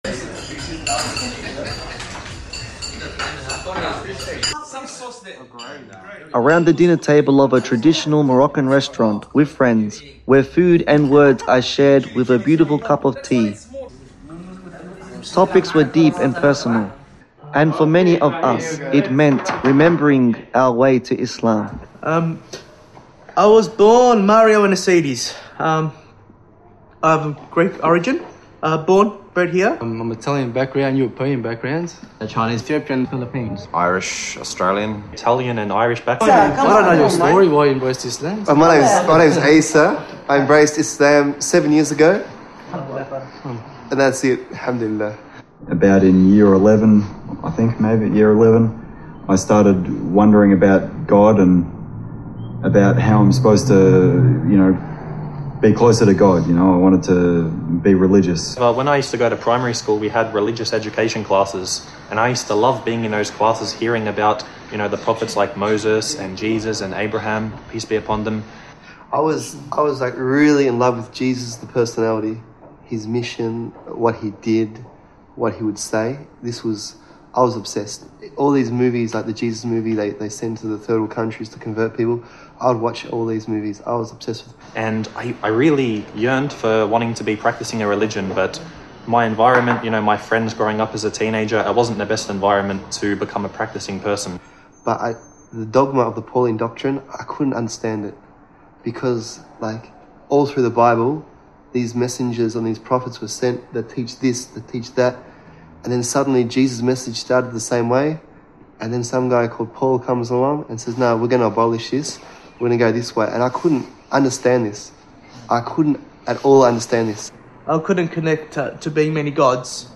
Around a dinner table in a traditional Moroccan restaurant, a group of Australian Muslims from remarkably diverse backgrounds share their journeys to Islam. Their stories shatter the myth that Islam is a religion for one ethnicity or culture, revealing instead a faith that speaks to every human heart regardless of background.
The converts gathered at this table represent an extraordinary cross-section of Australian society: Italian, Irish, Chinese, European, Filipino, and Indigenous Australian backgrounds.